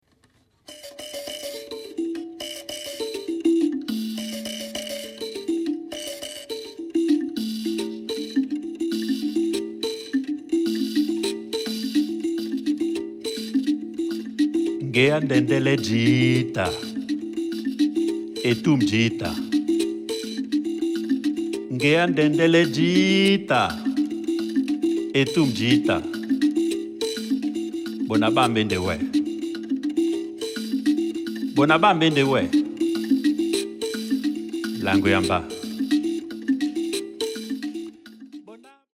La sanza est un idiophone à lamelles (ou lamellophone) originaire d’Afrique subsaharienne. Elle se compose de languettes métalliques ou végétales, fixées sur une plaque ou une caisse de résonance en bois, que le musicien fait vibrer par pincement avec les pouces (et parfois les index).